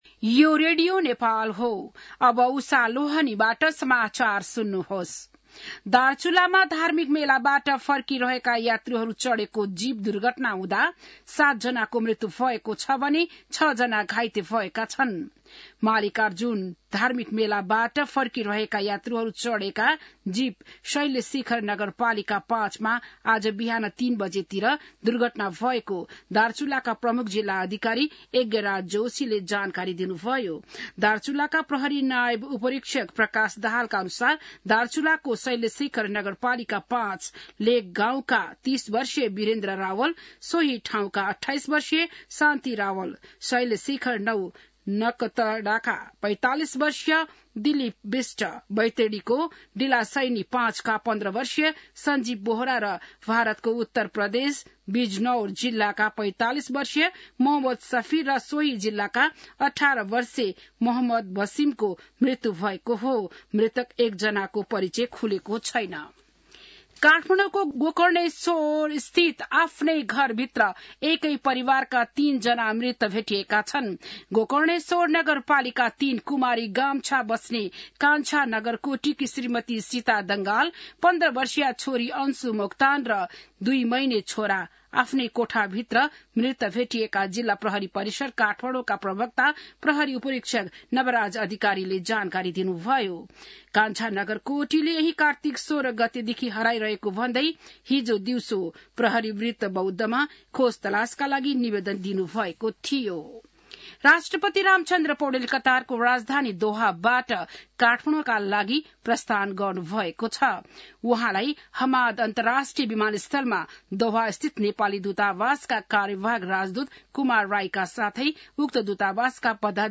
बिहान १० बजेको नेपाली समाचार : १ मंसिर , २०८१